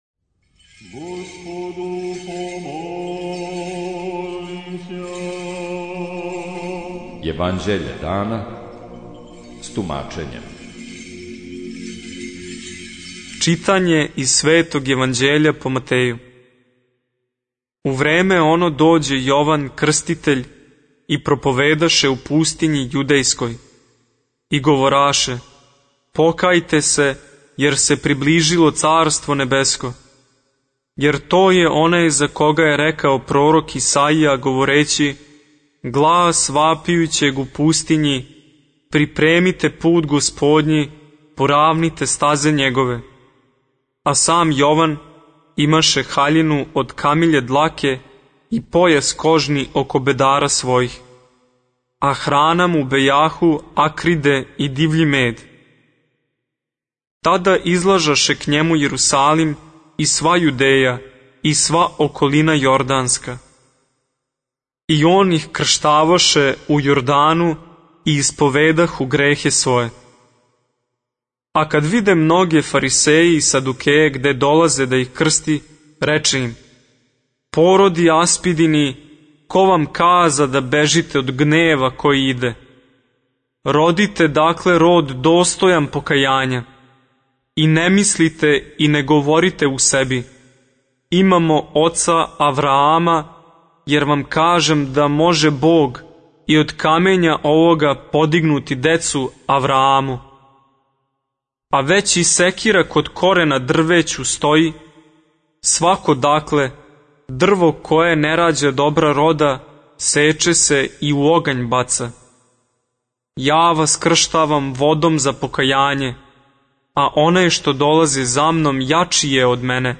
Јеванђеље дана
jevandjelje-dana-18-01-mt-zac-5.mp3